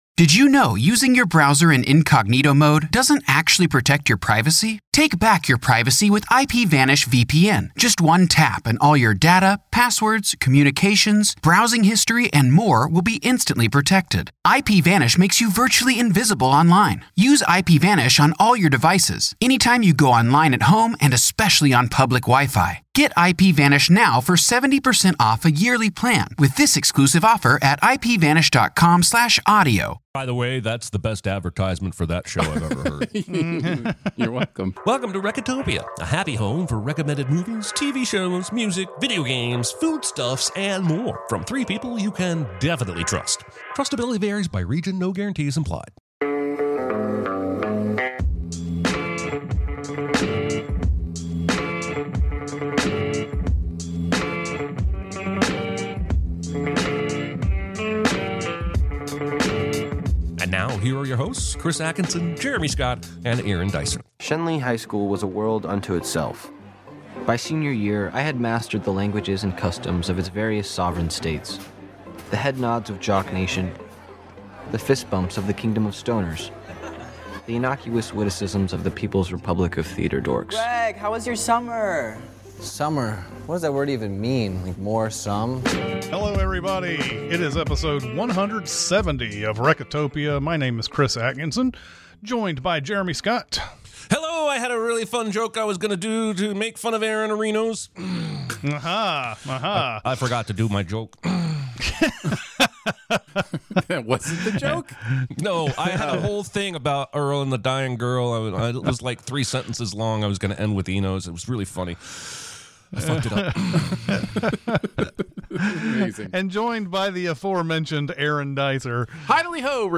Live From Audacy Studios